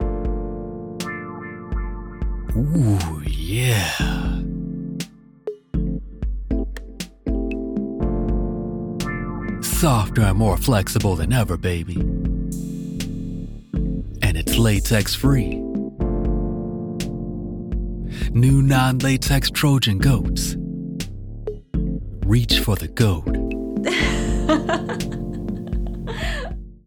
Contraceptive Brand - Smooth and Sexy Barry White/Isaac Hayes
North American, African American, Southern, Eastcoast
I record projects using the Focusrite Scarlet Solo, CM25 MkIII condenser mic and REAPER DAW.